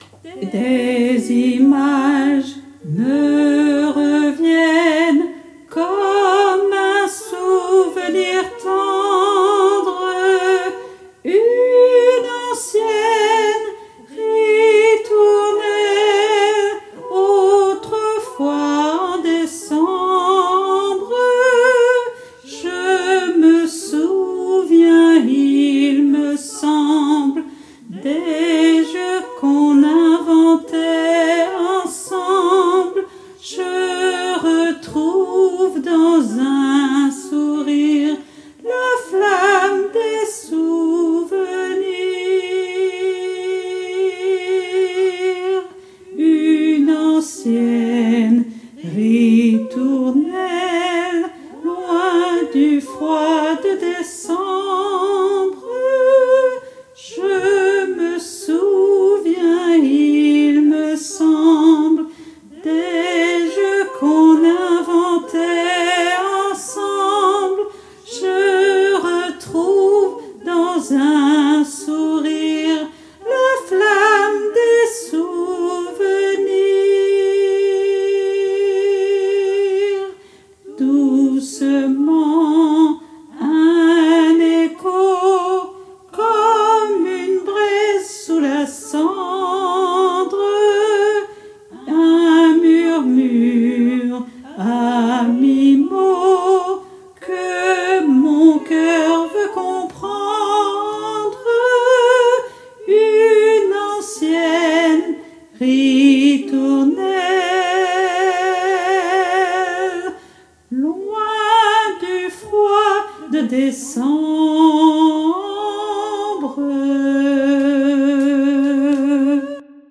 MP3 versions chantées
Hommes et autres voix en arrière-plan